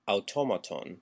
Ääntäminen
IPA : /kən.ˈtræp.ʃən/